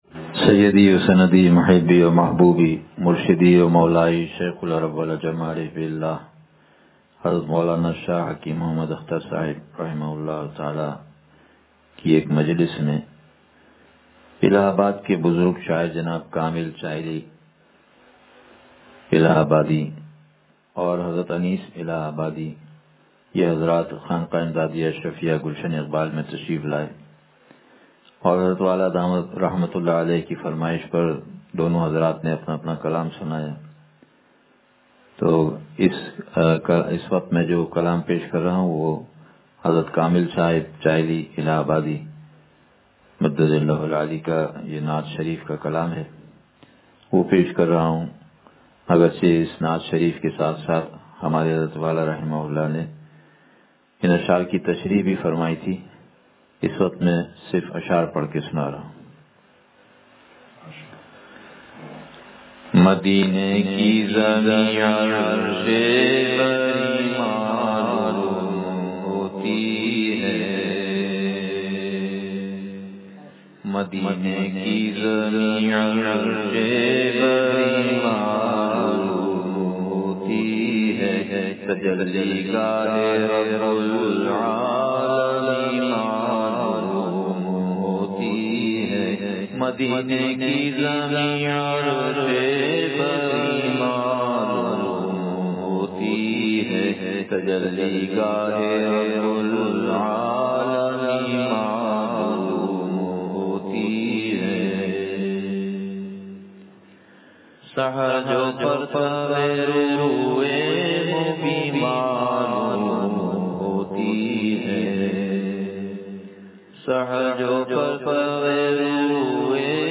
نعت شریف – مدینے کی زمیں عرشِ بریں معلوم ہوتی ہے – معارف الاکابر سے ملفوظ – دنیا کی حقیقت – نشر الطیب فی ذکر النبی الحبیب صلی اللہ علیہ وسلم